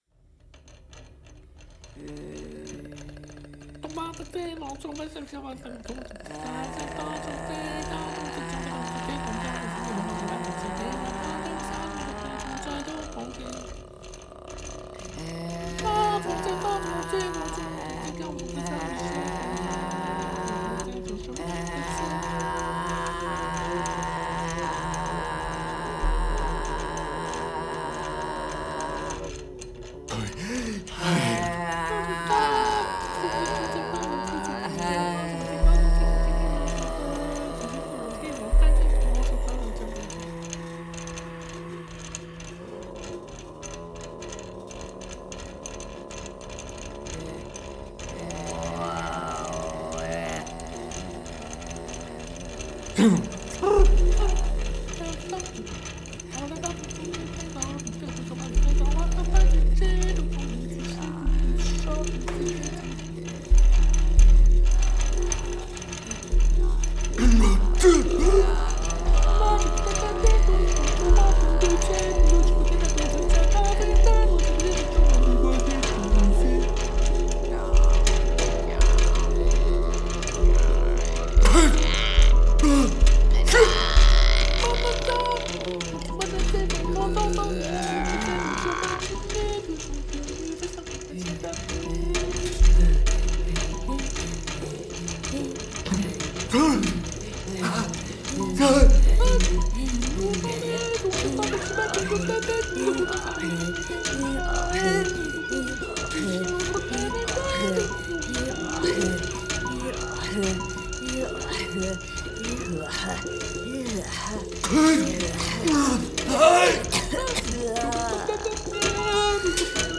the breath and voices
the sound textures of skins, wood, and metal
voice
drums